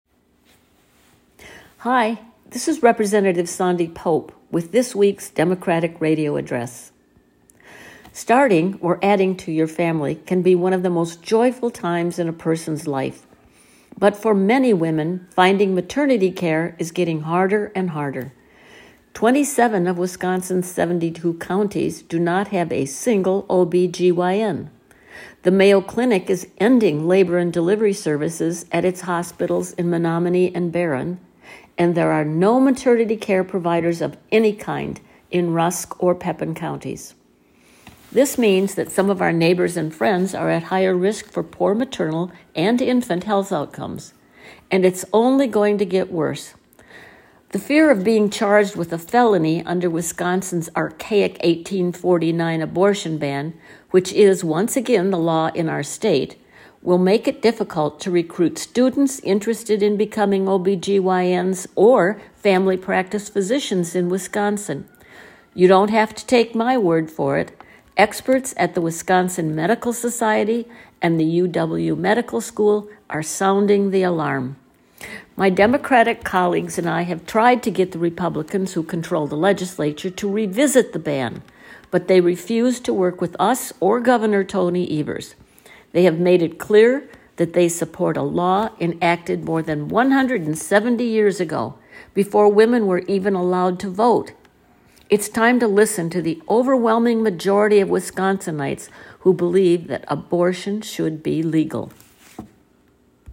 Weekly Dem radio address: Rep. Pope says medical experts are sounding the alarm on the lack of obstetricians and gynecologists in Wisconsin - WisPolitics